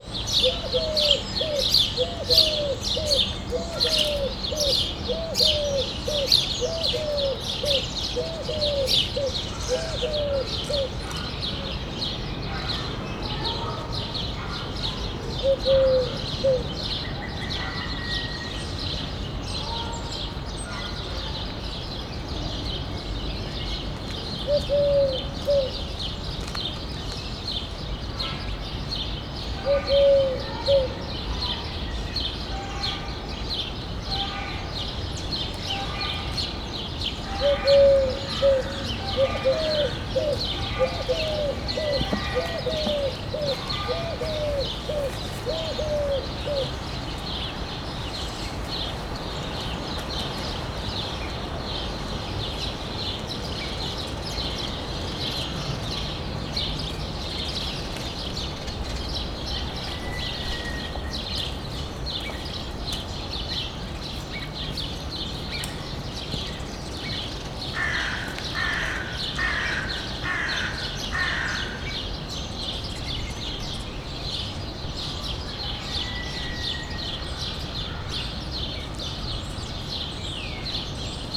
balkanigerle_szurkevarju01.21.WAV